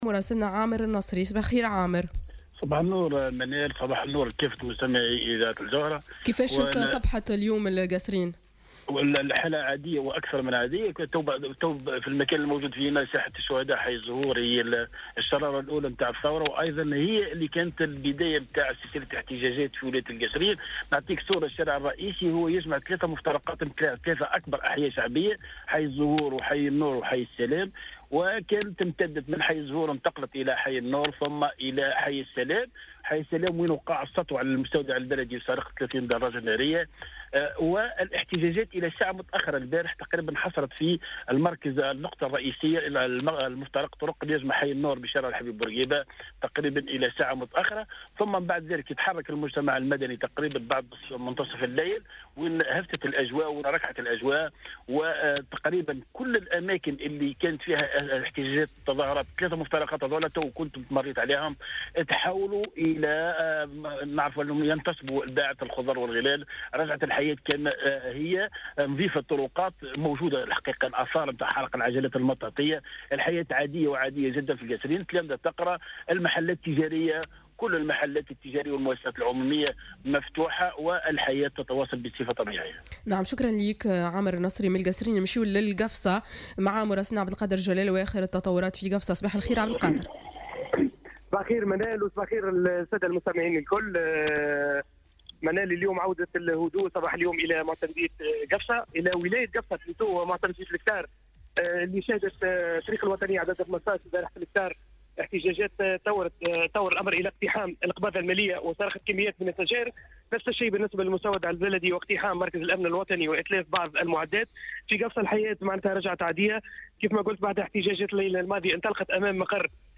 وفي مراسلة مباشرة من ساحة الشهداء بحي الزهور على مستوى مفترق طريق يجمع بين أهم الأحياء بمدينة القصرين، اكد مراسلنا عودة الهدوء مشيرا إلى توجه التلاميذ الى مدارسهم في هذه الساعة وانتصاب الباعة المتجولين وسط المدينة بشكل عادي بالضافة إلى فتح المحلات التجارية أبوابها صباح اليوم الثلاثاء.